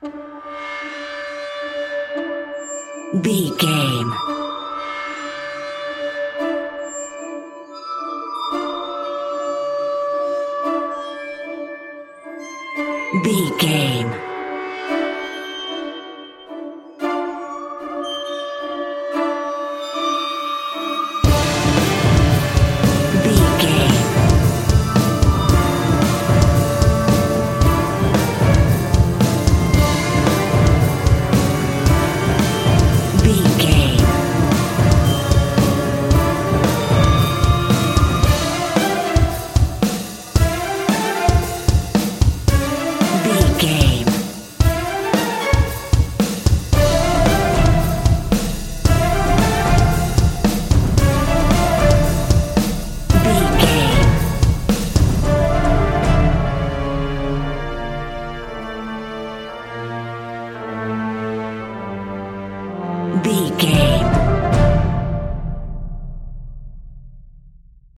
Epic Action Horror Film Music.
In-crescendo
Aeolian/Minor
D
tension
ominous
dark
eerie
synthesiser
percussion
strings
drums
pads